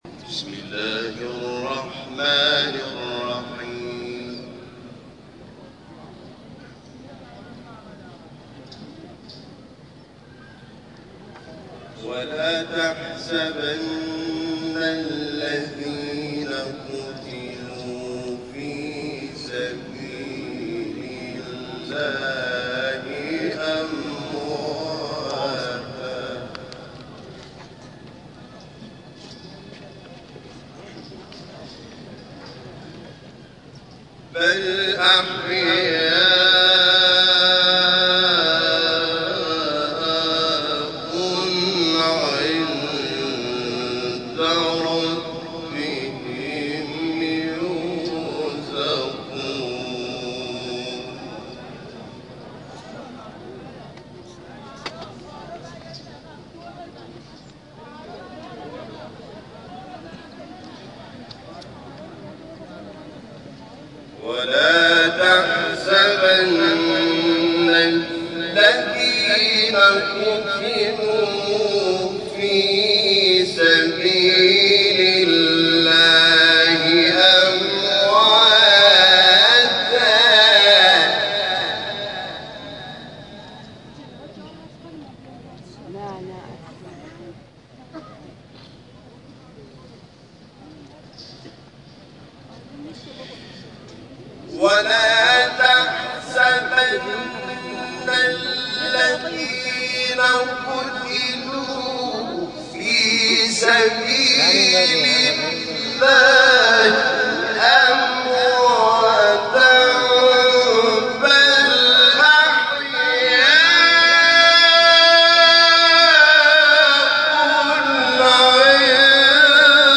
گروه جلسات و محافل: مراسم تشییع پیکر شهید محسن حججی، شهید مدافع حرم با تلاوت قرآن حامد شاکرنژاد، برگزار شد.
تلاوت